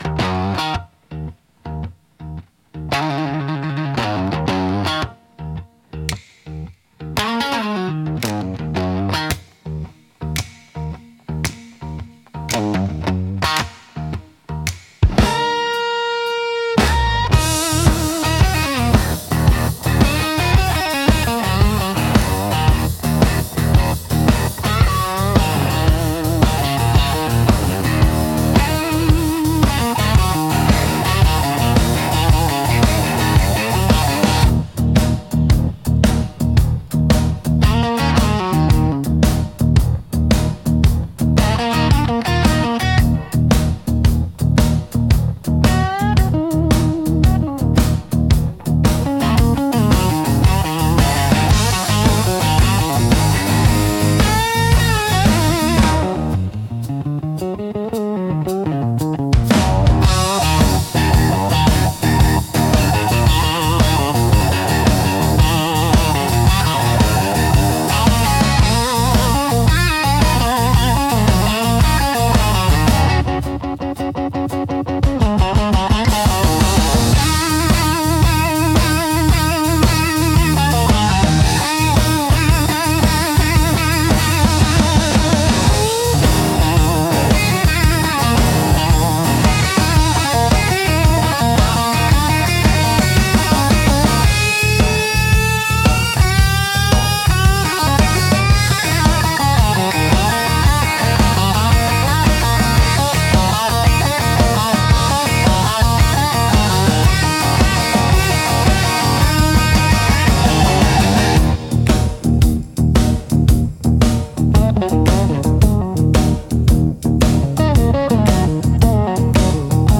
Instrumental - Unsaid Goodbye in 12_8 Time